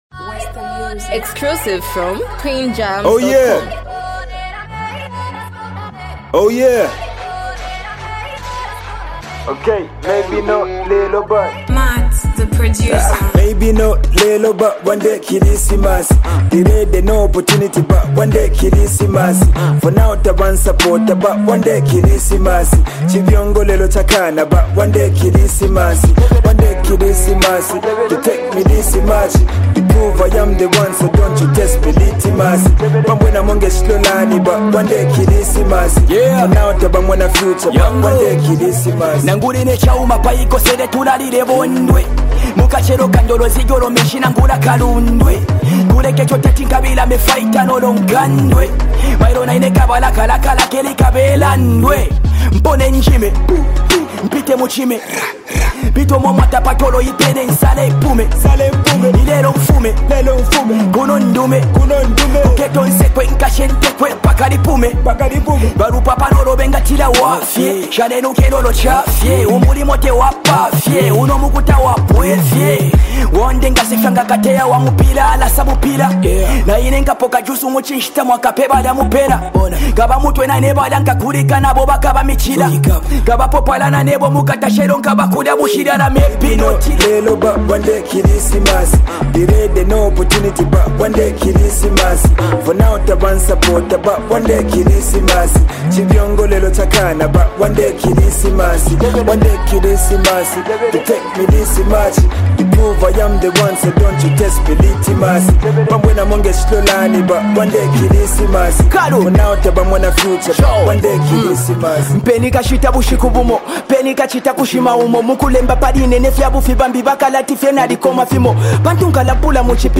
a reflective and emotionally driven song